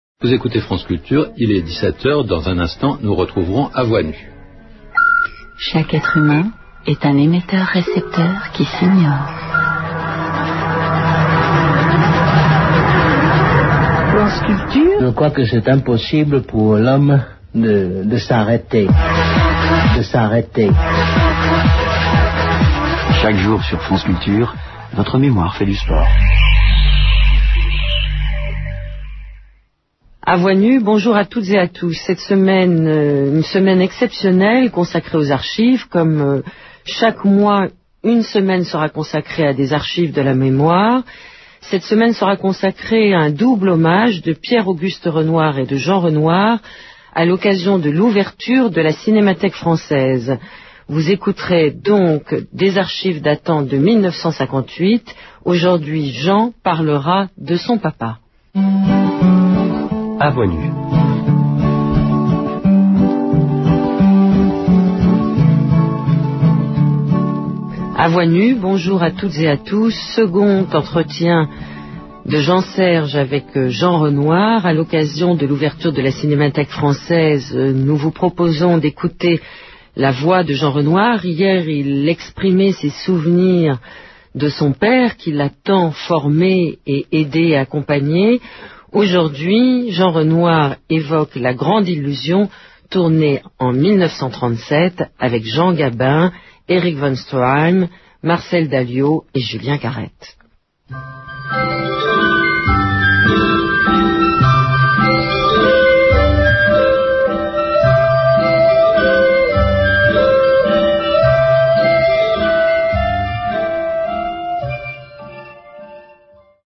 - jingles stupides de transition qui remplacent les speakerines éliminées par L.A.
- redoublement de la présentation (apparemment L.A. ne pratique pas le direct)